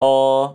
Forskare har upptäckt att den exakta tidsmätningen som en person kan efterlikna och uppnå är rörelse, det vill säga, när en person säger: قَ قَ (QaQa)   tar det precis lika lång tid som att säga قا (Qaa)